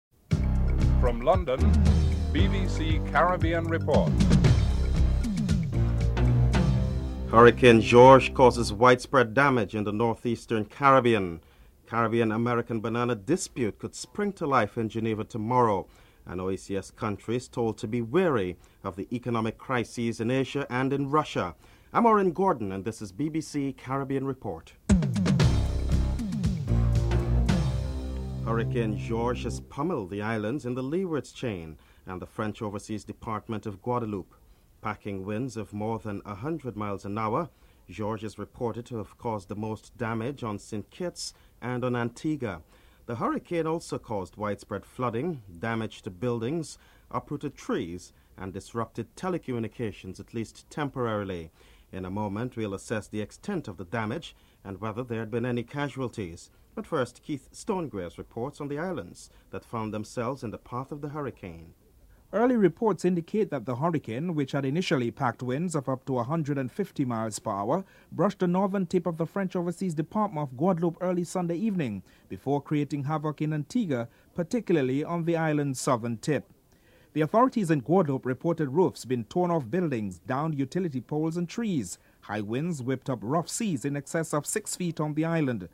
1. Headlines (00:00-00:27)
5. The man appointed by Caricom to keep Guyana's political parties at the negotiating table has arrived in Guyana and has begun his work. Former Attorney General of Barbados Morris King is interviewed (14:13-15:21)